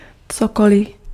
Ääntäminen
GenAm: IPA : /wʌtˈɛvɚ/